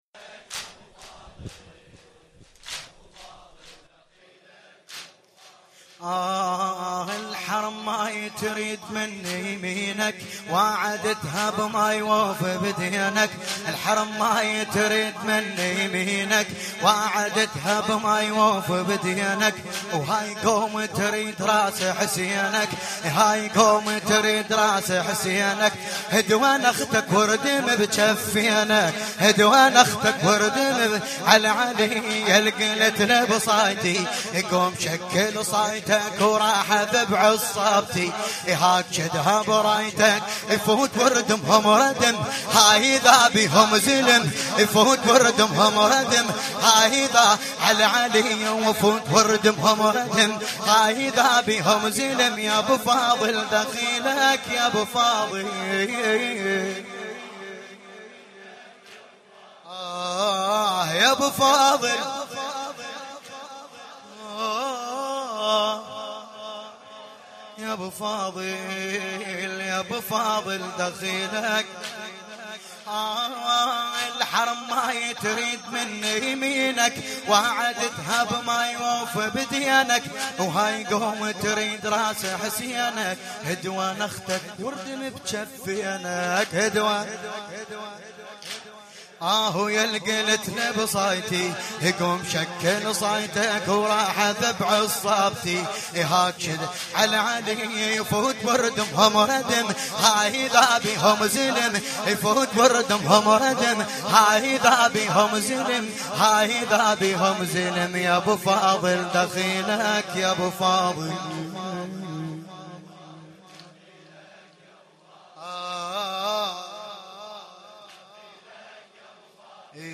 لطميات متفرقة